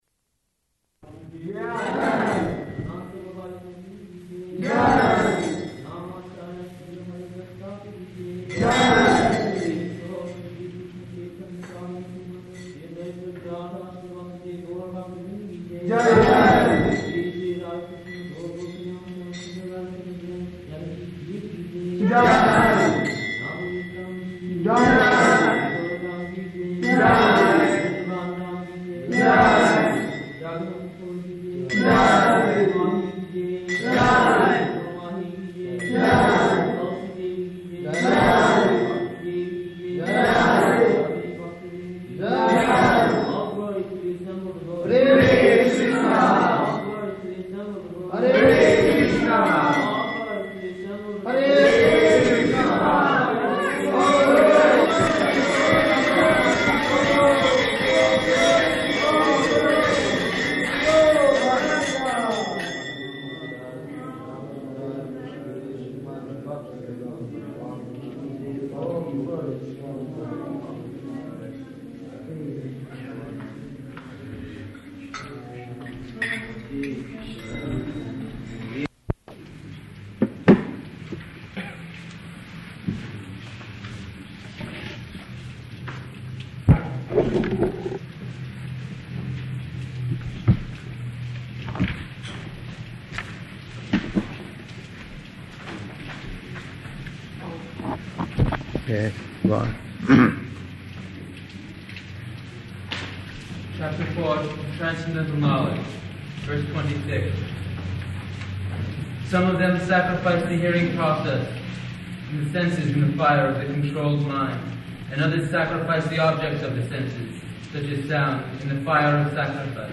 Bhagavad-gītā 4.26–30 --:-- --:-- Type: Bhagavad-gita Dated: January 13th 1969 Location: Los Angeles Audio file: 690113BG-LOS_ANGELES.mp3 [ prema-dhvani prayers led by devotee] [break] [01:43] Prabhupāda: Page?